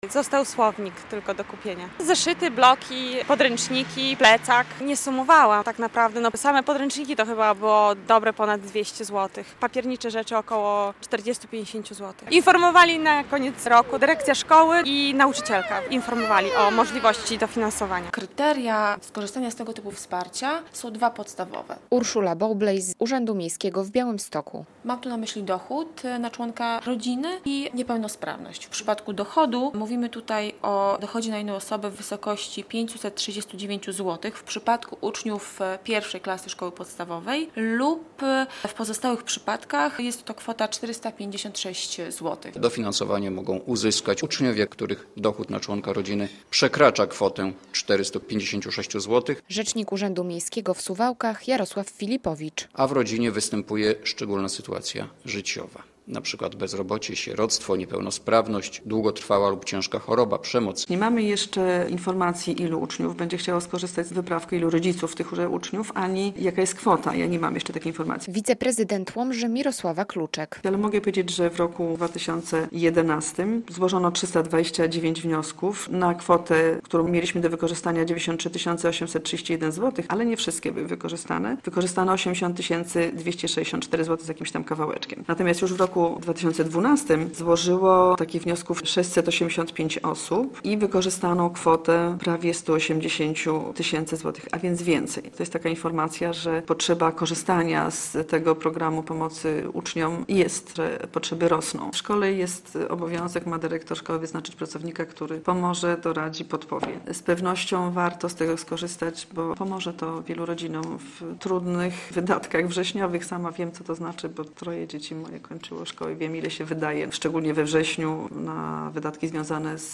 Dofinansowanie do wyprawek szkolnych - relacja